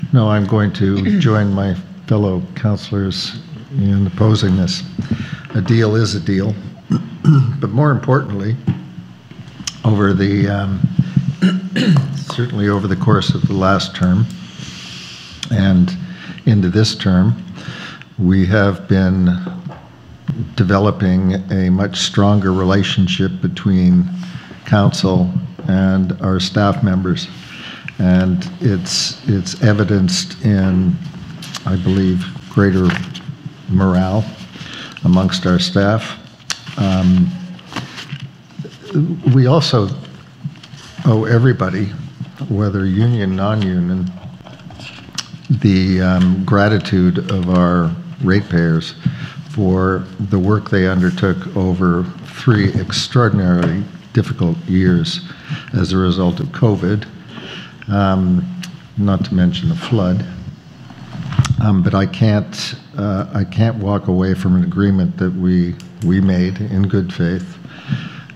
Concerning non-union staff, Wellington Councillor Corey Engelsdorfer proposed a motion to set their COLA at 4 per cent (for a savings of $224,000). In response, Mayor Steve Ferguson had the following to say: